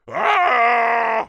Ohno1.wav